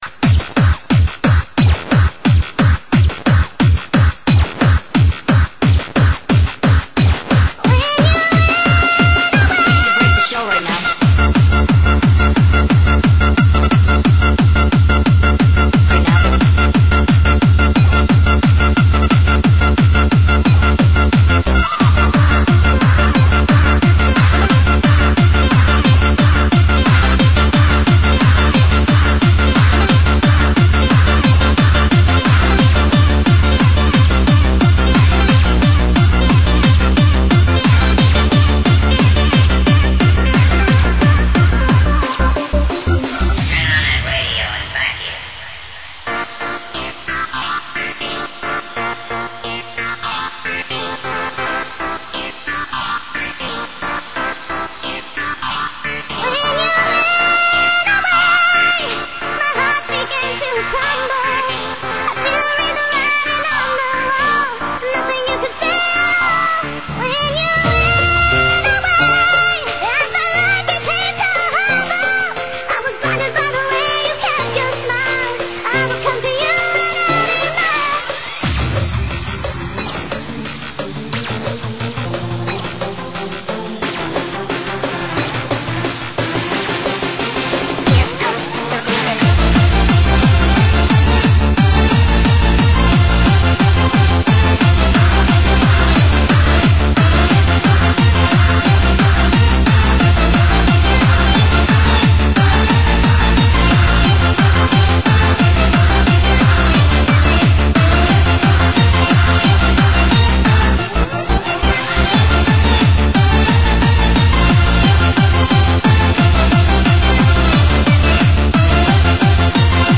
栏目： 现场串烧